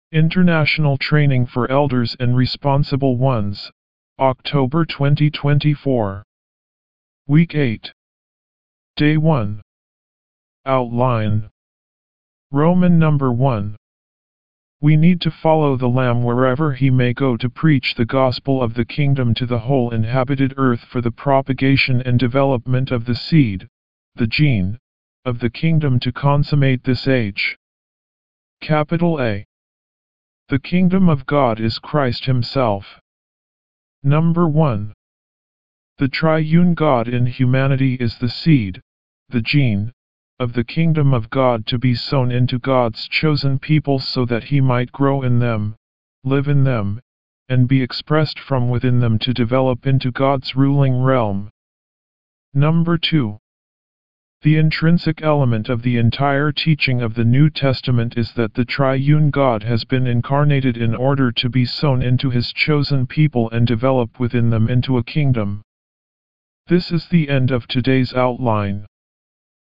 D1 English Rcite：